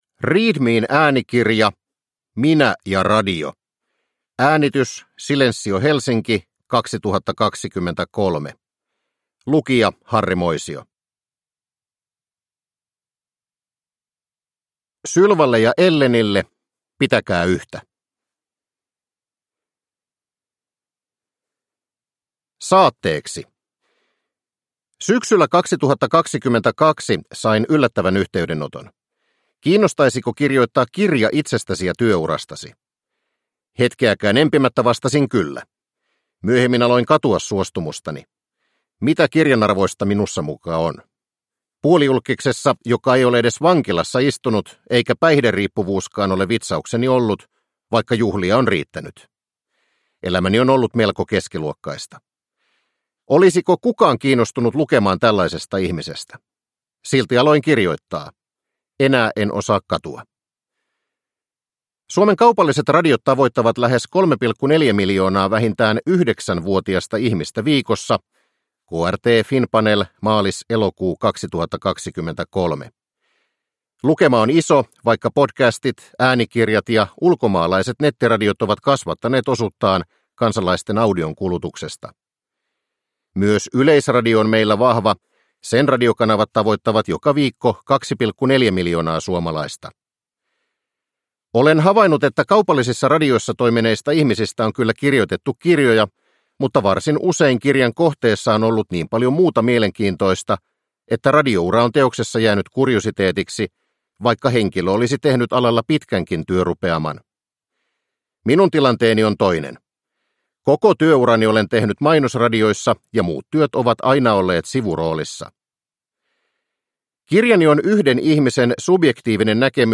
Minä ja radio (ljudbok